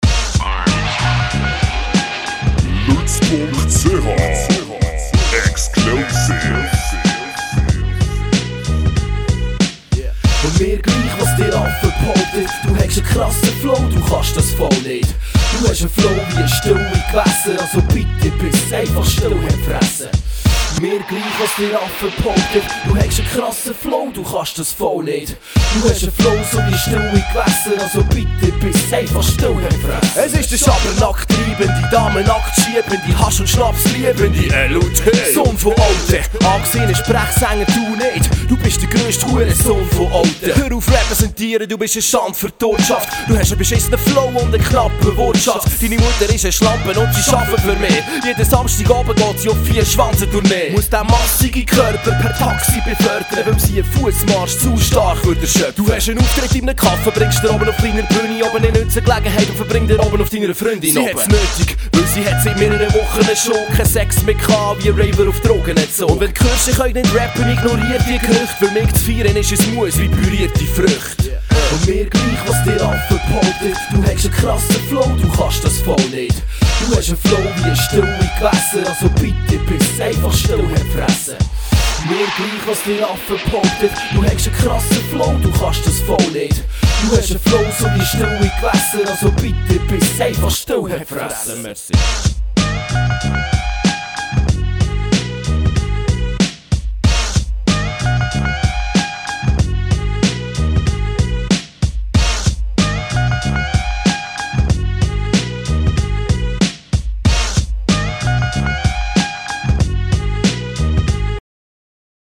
16 Takte zu aktuellen Geschehnissen: